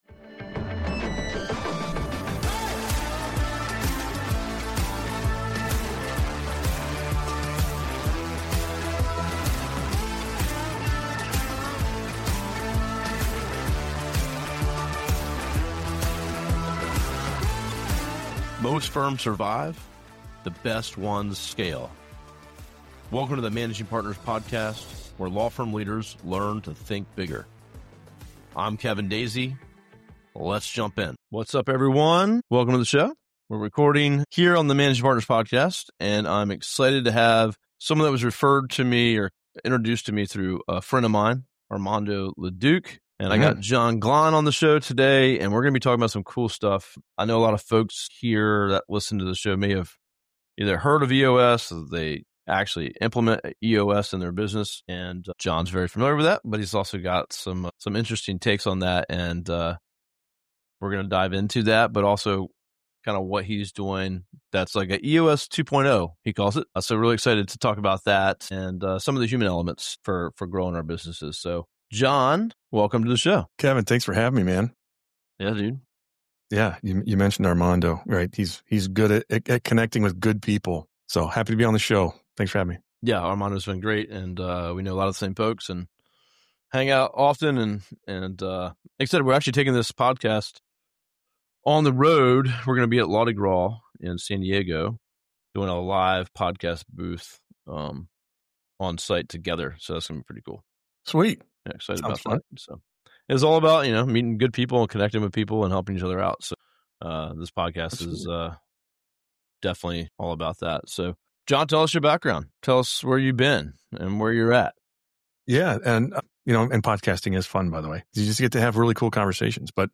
If you own a law firm and want structured growth without losing control of your life, this conversation gives you a clear path.